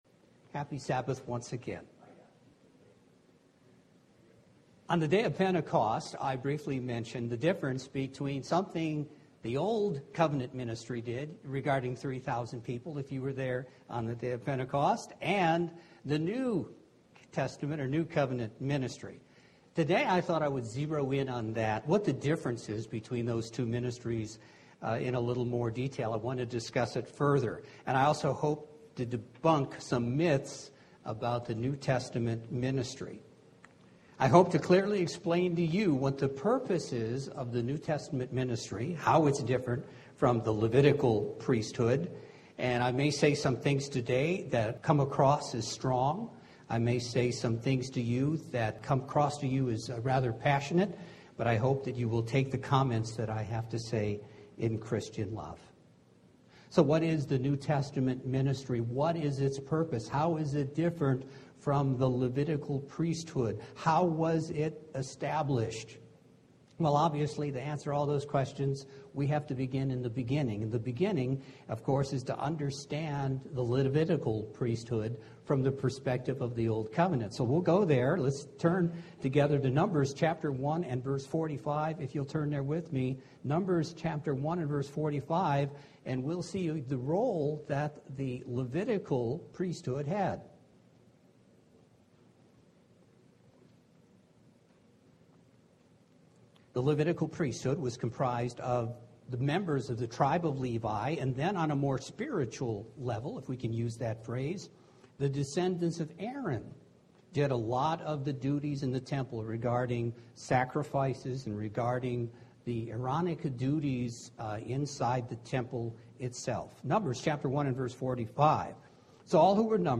UCG Sermon ministry ministry of reconciliation servant Transcript This transcript was generated by AI and may contain errors.